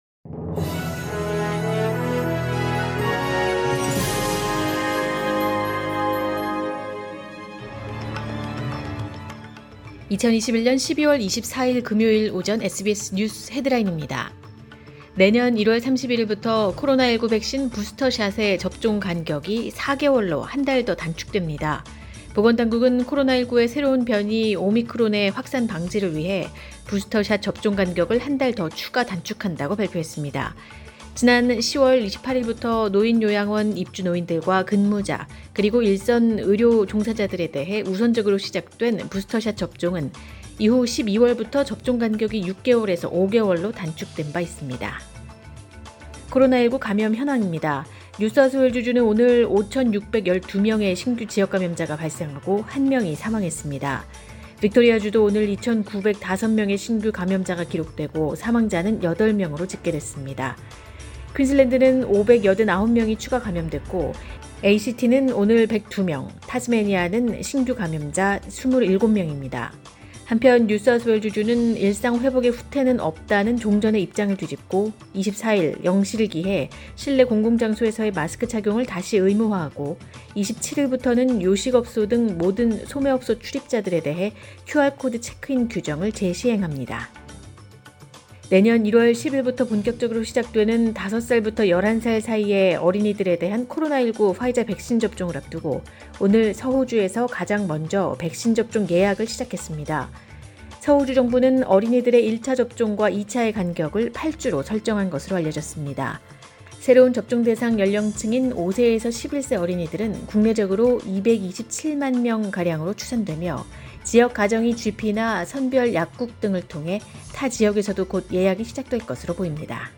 2021년 12월 24일 금요일 오전의 SBS 뉴스 헤드라인입니다.